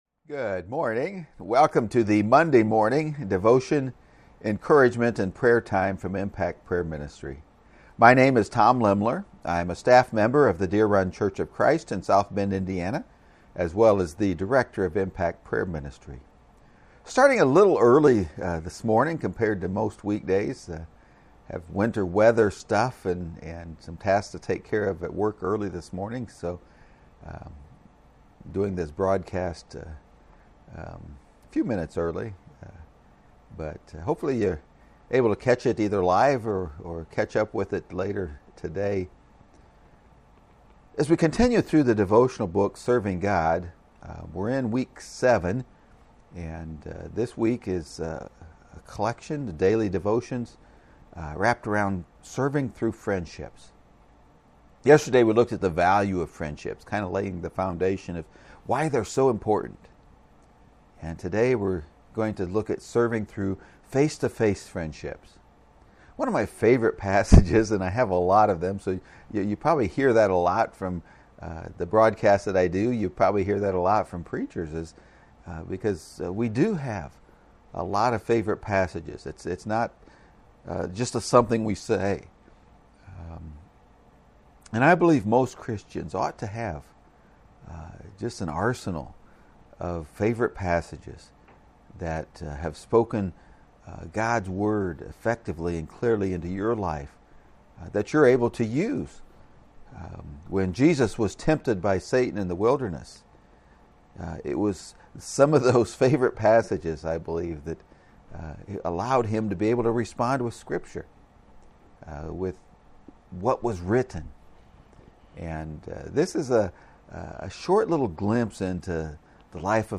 You can find the live video feeds of these encouragement and prayer times on Impact Prayer Ministry’s Facebook page and YouTube channel.